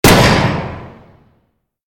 LB_camera_shutter_2.ogg